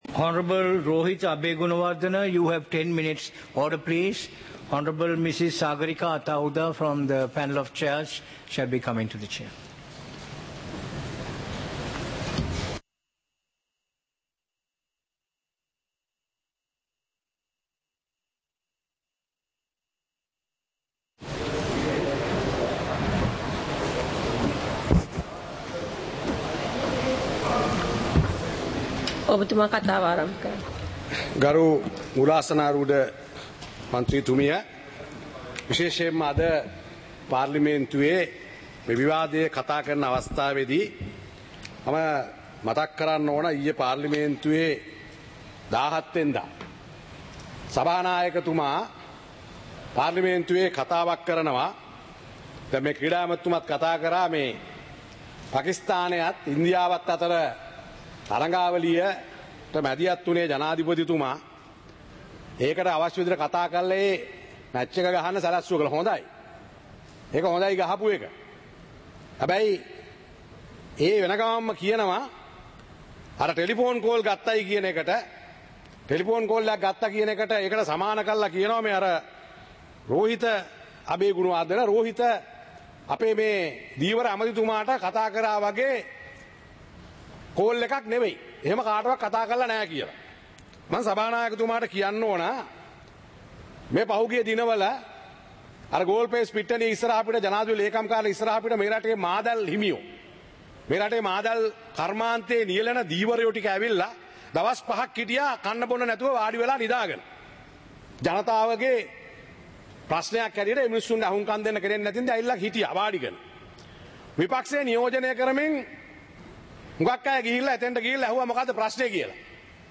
சபை நடவடிக்கைமுறை (2026-02-18)